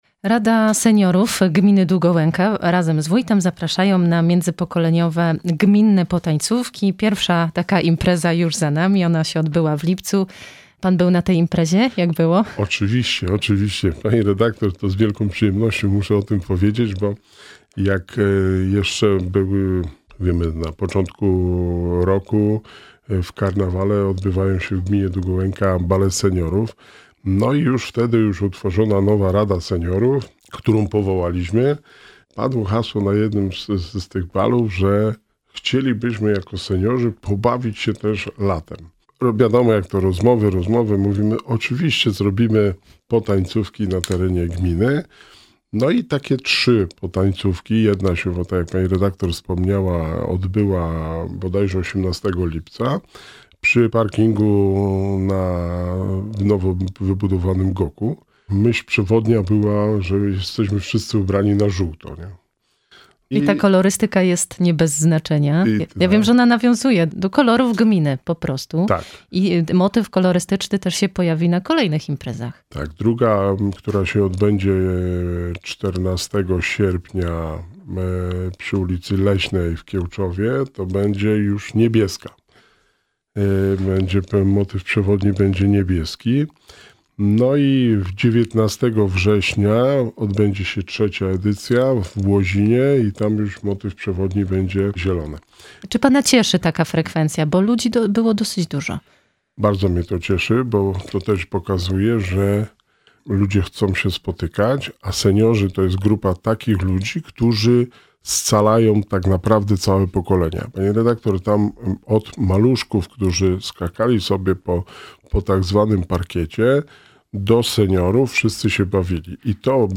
Na koniec rozmawiamy z Wójtem o bieżących inwestycjach. Jeszcze przez kilka miesięcy potrwa rozbudowa podstawówki w Wilczycach.